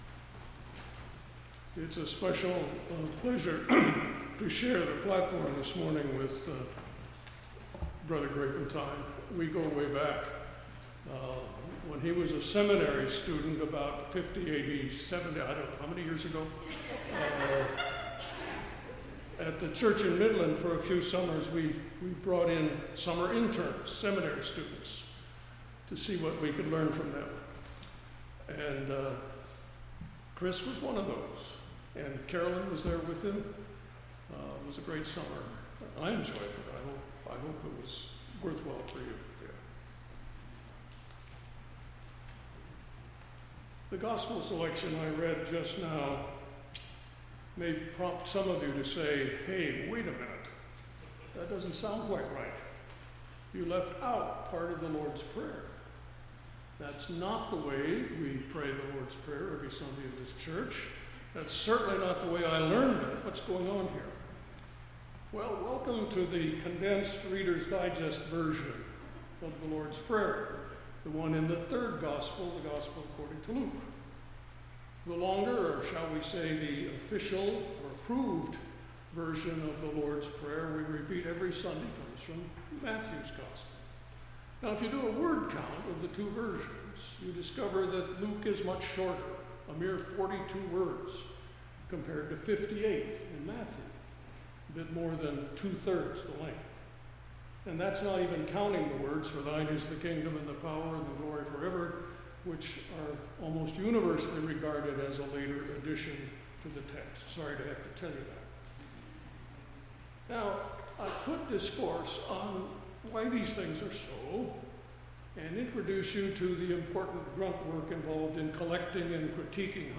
7-24-16-sermon.mp3